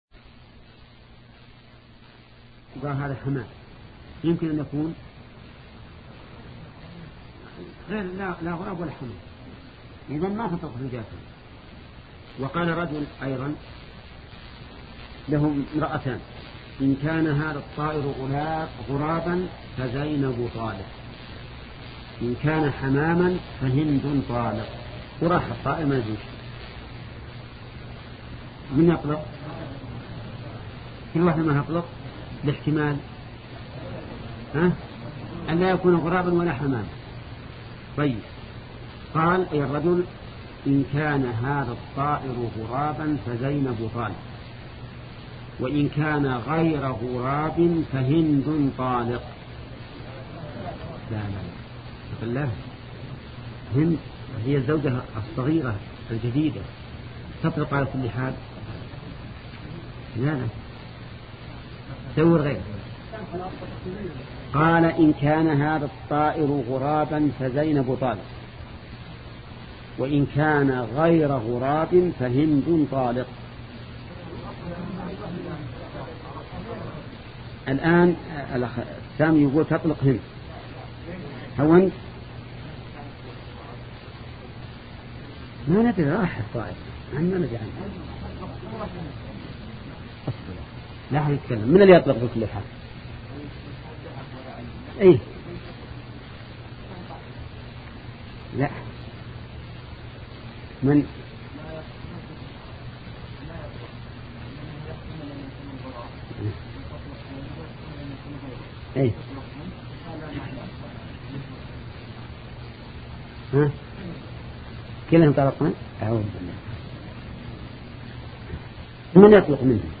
سلسلة مجموعة محاضرات شرح القواعد المثلى لشيخ محمد بن صالح العثيمين رحمة الله تعالى